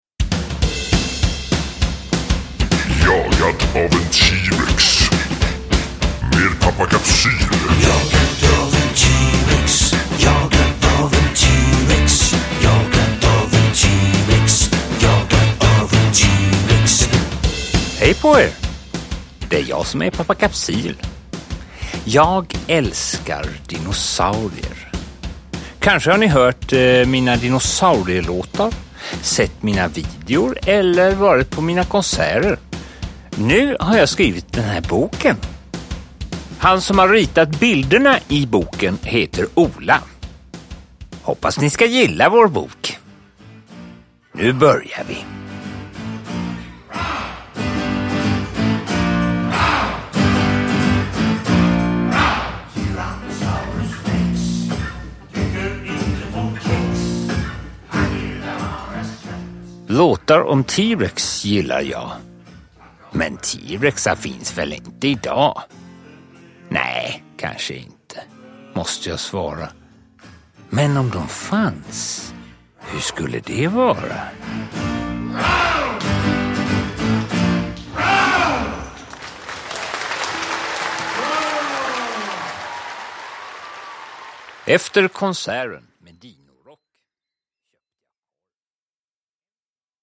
Jagad av en T-Rex – Ljudbok